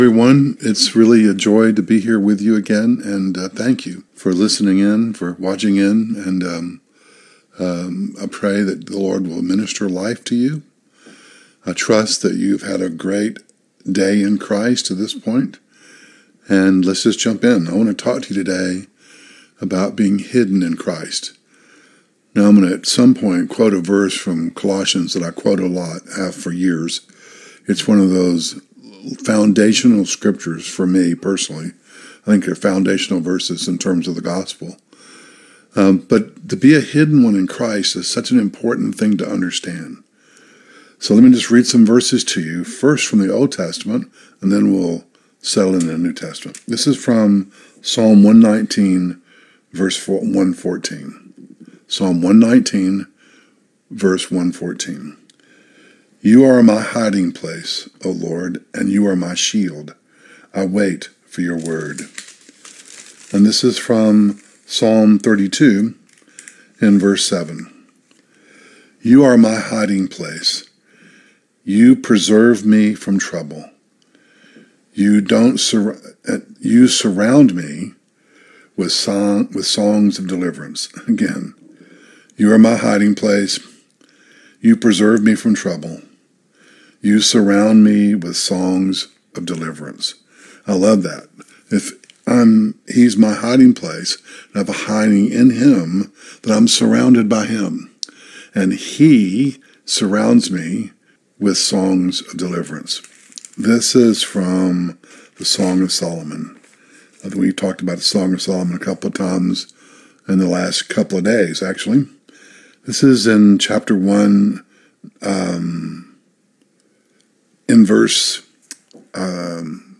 Originally on Facebook Live 5/30/2025